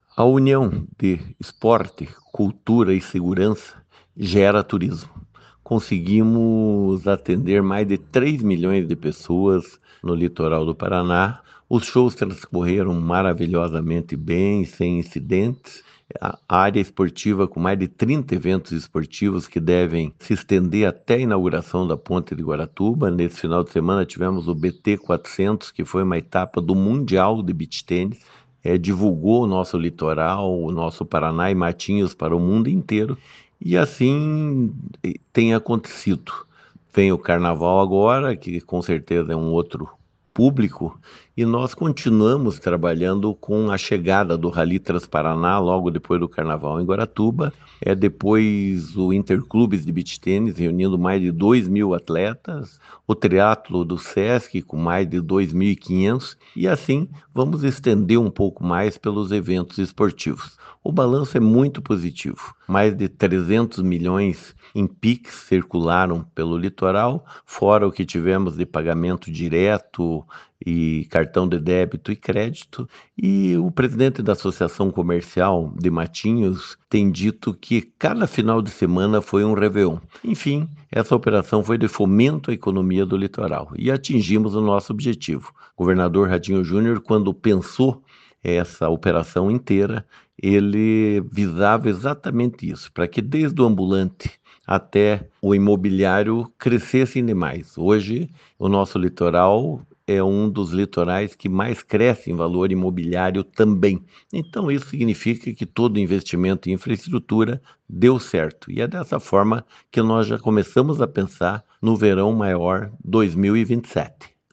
Sonora do secretário do Esporte e coordenador do Verão Maior Paraná, Helio Wirbiski, sobre os recordes de público do evento no Litoral